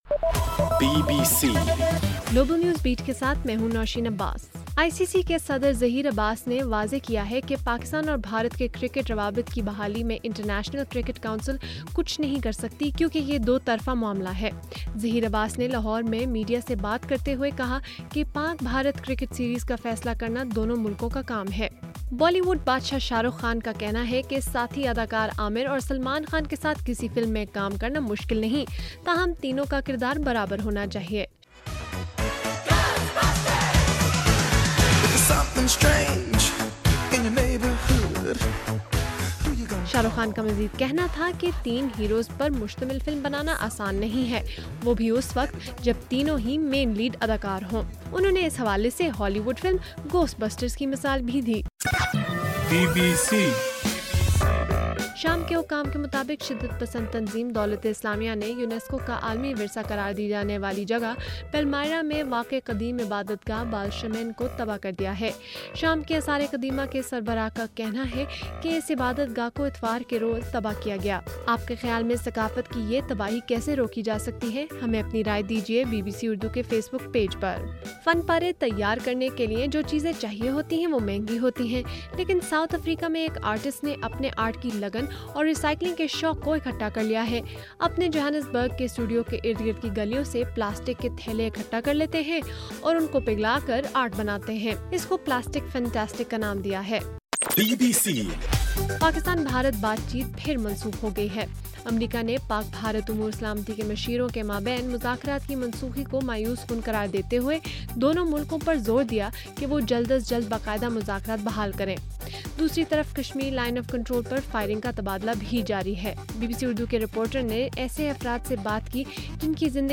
اگست 24: رات 12 بجے کا گلوبل نیوز بیٹ بُلیٹن